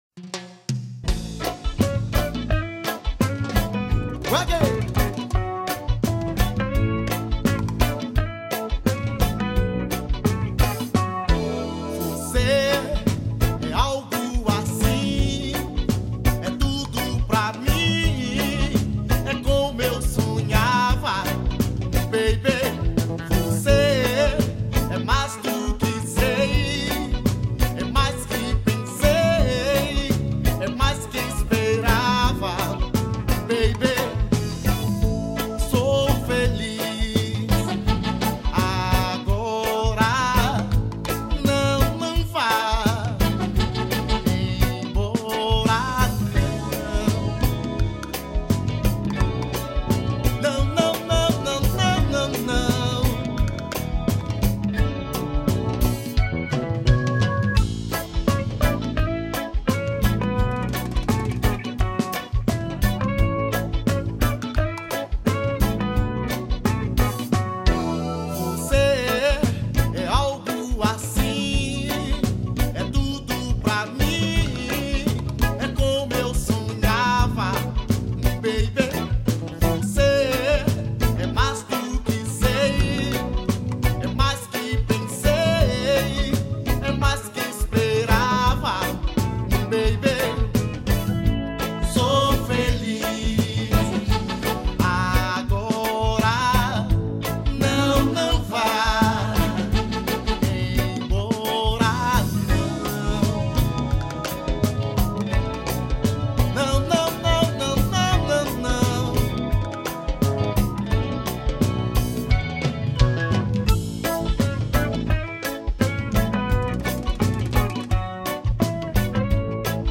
EstiloAxé